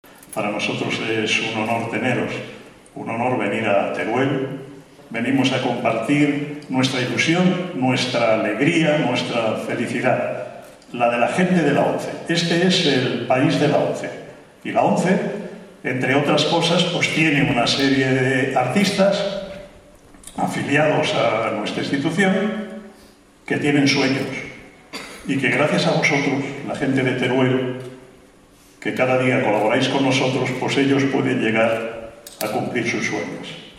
durante la presentación del concierto inaugural de esta Bienal, que tuvo lugar en el Teatro Marín de Teruel